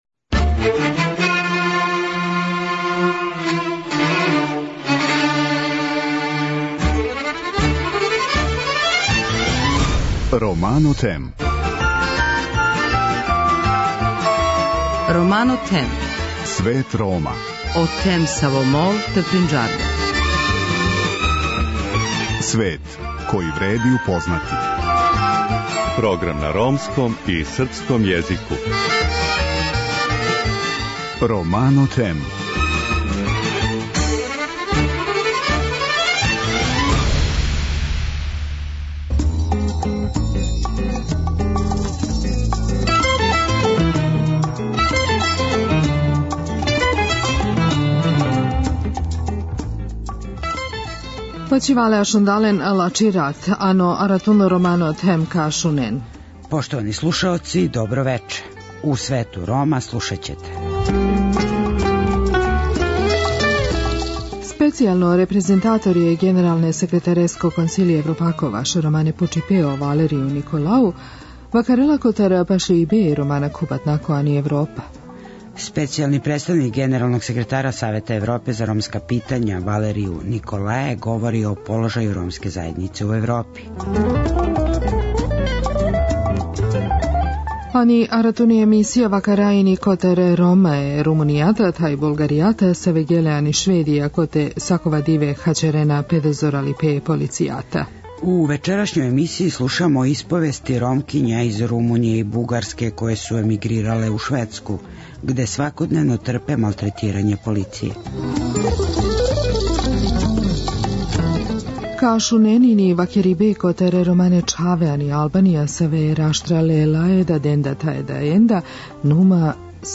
У вечерашњој емисији слушамо исповести Ромкиња из Румуније и Бугарске које су емигрирале у Шведску, где свакодневно трпе малтретирања полиције.